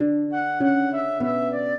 flute-harp
minuet4-7.wav